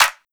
CLAP_KNIGHT_IN_SHINING_2.wav